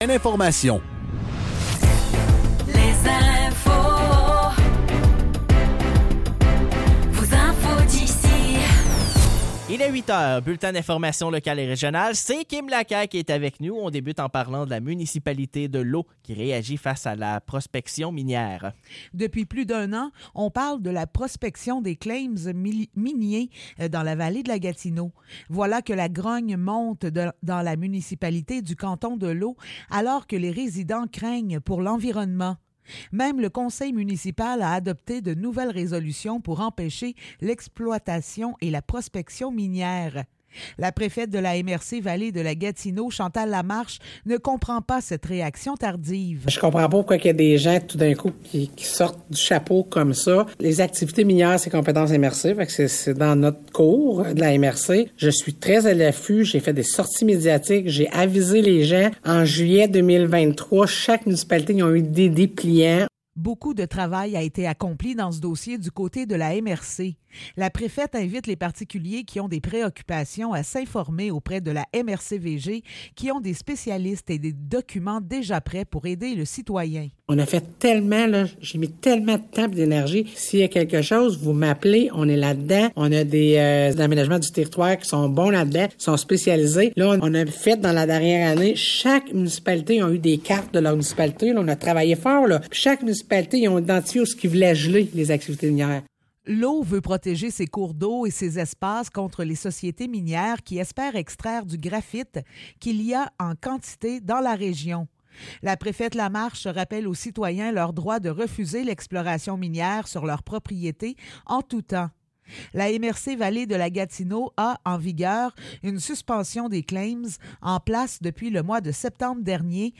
Nouvelles locales - 29 janvier 2024 - 8 h